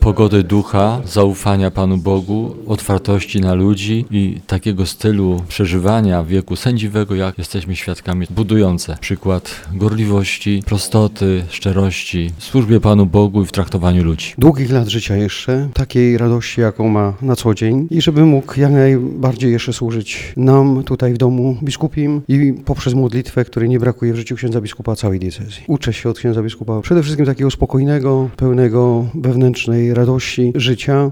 18biskupi_zyczenia.mp3